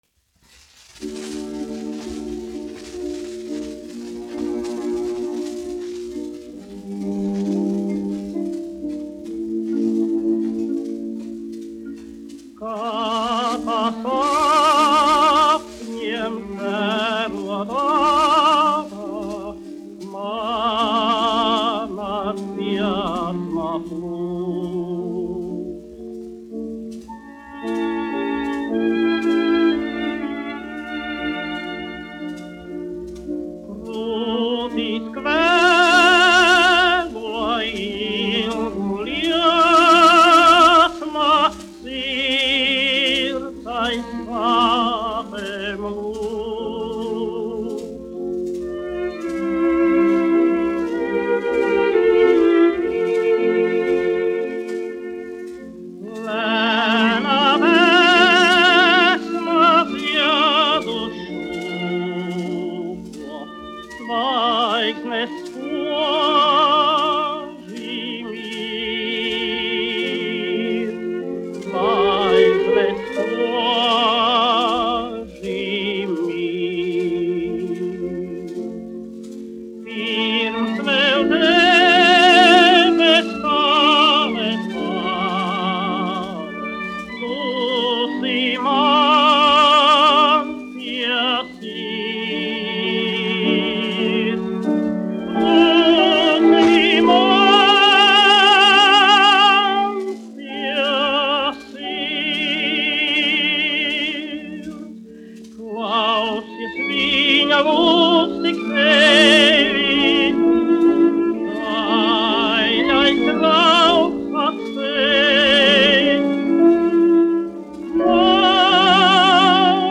1 skpl. : analogs, 78 apgr/min, mono ; 25 cm
Dziesmas (augsta balss) ar klavieru trio
Latvijas vēsturiskie šellaka skaņuplašu ieraksti (Kolekcija)